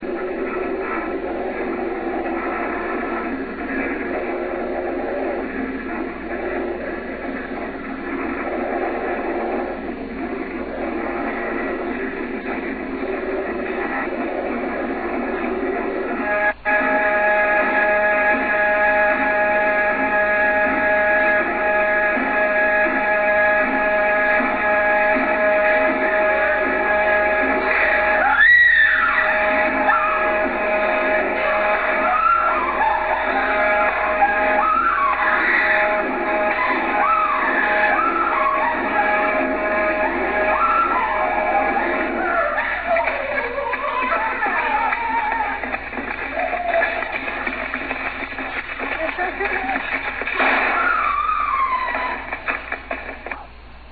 It obviously started with a lot of linked ideas but the actual performance follows the cues on an audio tape.
At 14:42 the final alarm starts and the chicken is decapitated.